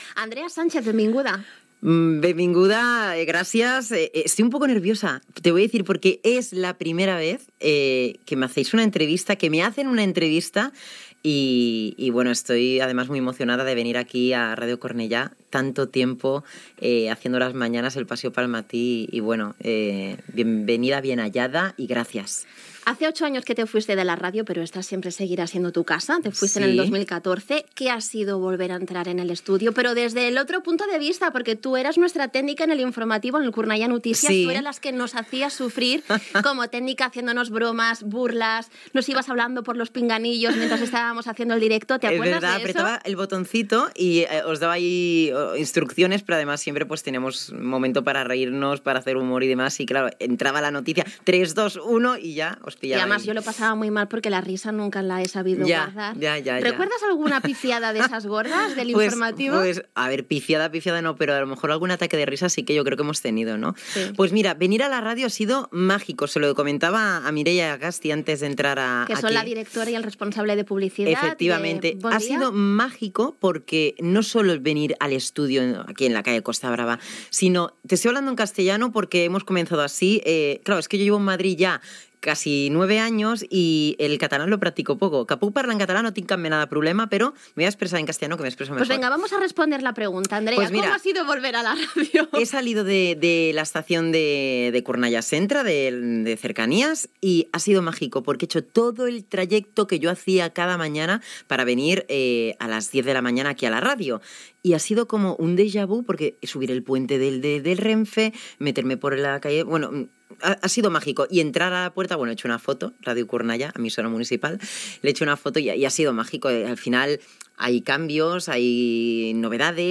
Entrevista
Info-entreteniment